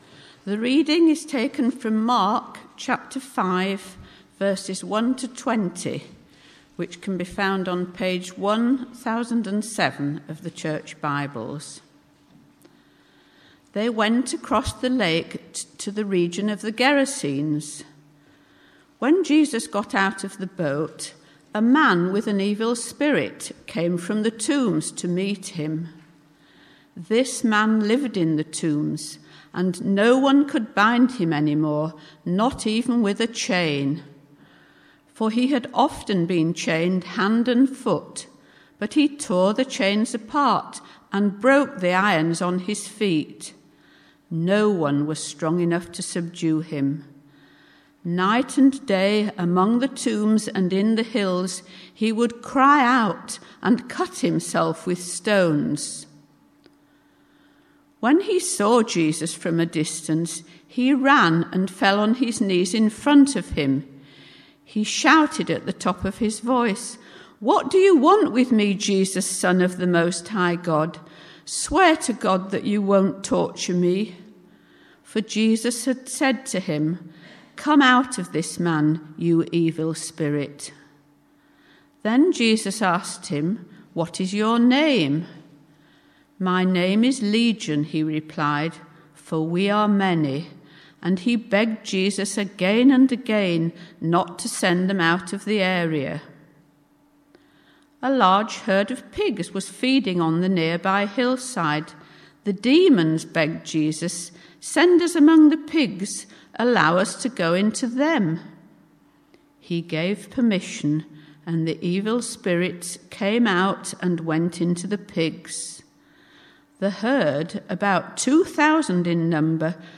Bible Text: Mark 5:1-20 | Preacher